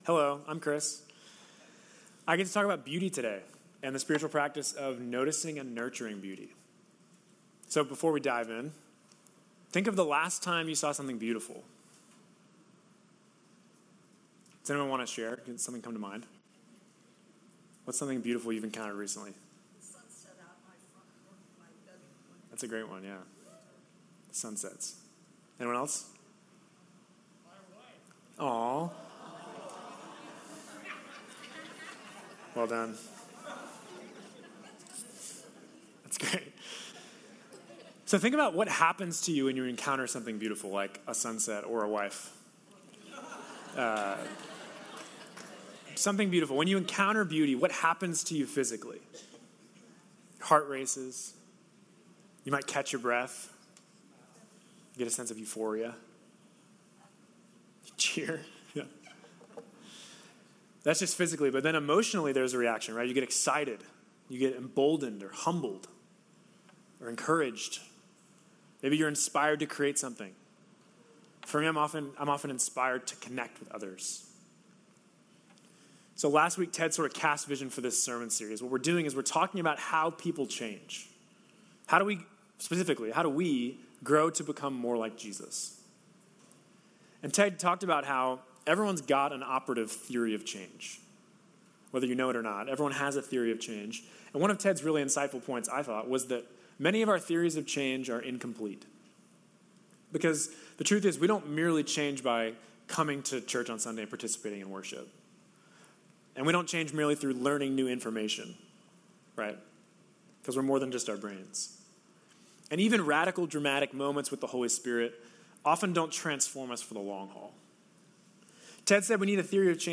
This week’s sermon is a recommendation for the spiritual practice of Noticing and Nurturing Beauty with tips for how to try it for yourself. This practice was something Jesus did regularly, and it can help us to appreciate the beauty all around us in God’s world.